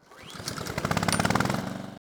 mower_start.wav